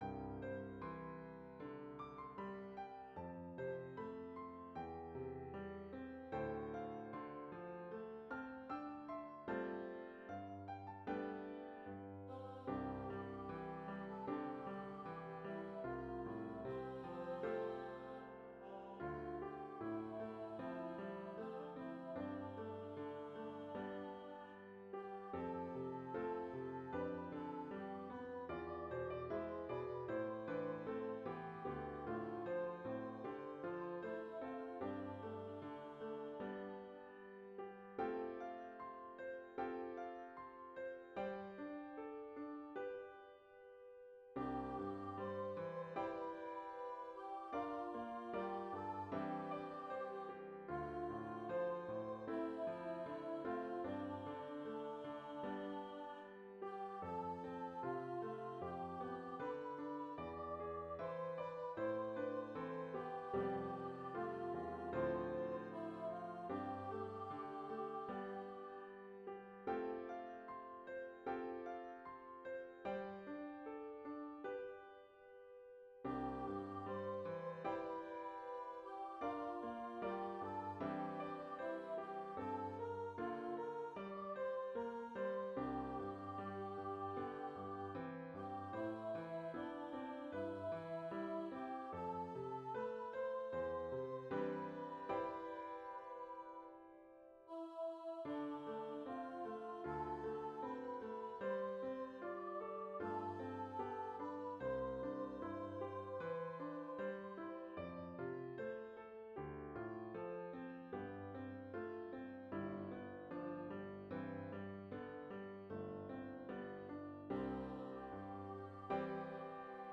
Medium Voice/Low Voice